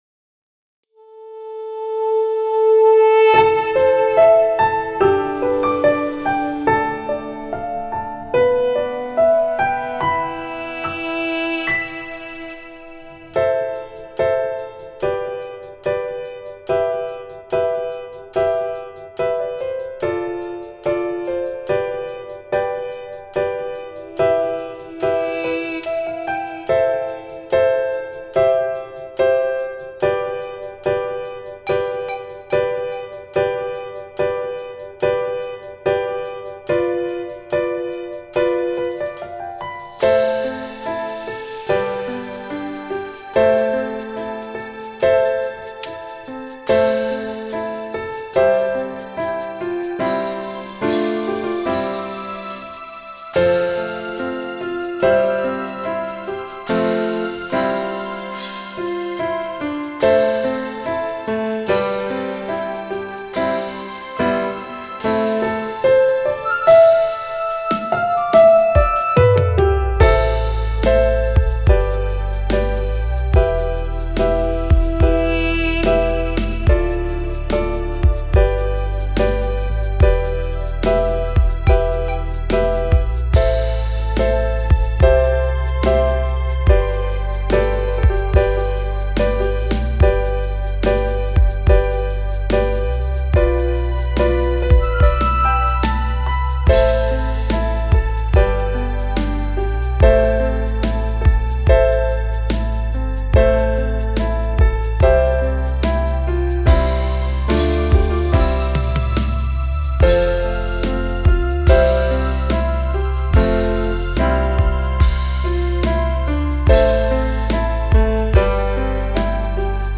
Поп музыка, этно.
Я новенький в сведении и мастеринге, поэтому прошу указать на все ошибки и описать методы их исправления.